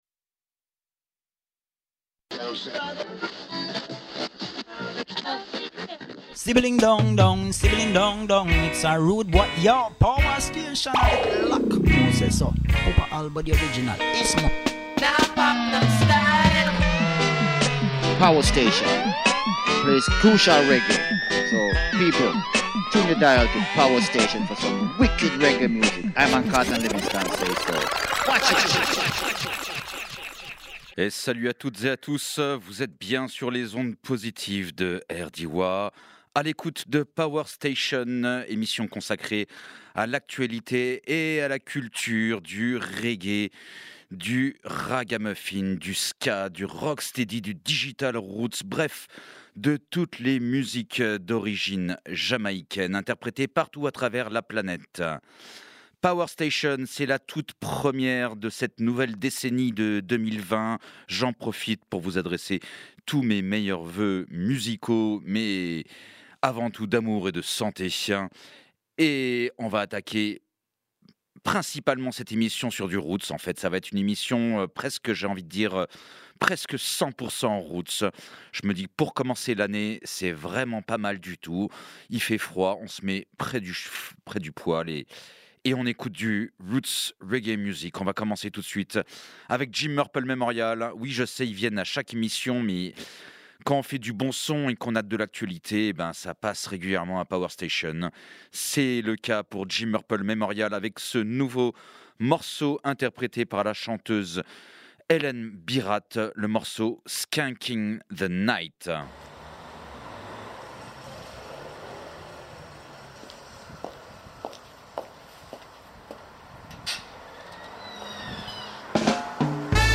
dub , reggae , ska